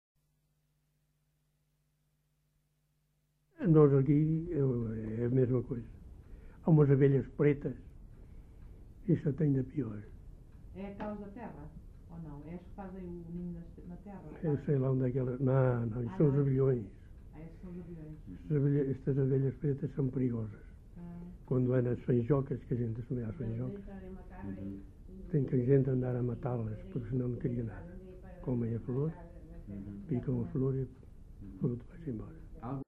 LocalidadeFigueiró da Serra (Celorico da Beira, Guarda)